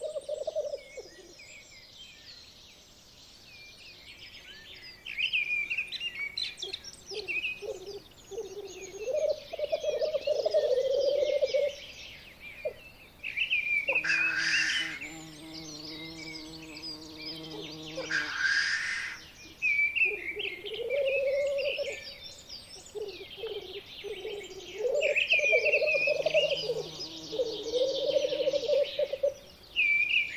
Cette création sans commentaire ni musique accompagnera les auditeurs durant 72 minutes à l'écoute des plus belles découvertes sonores au fil d'une année dans cette magnifique région qu'est le Diois en Drôme.
2 -- Naturophonie printanière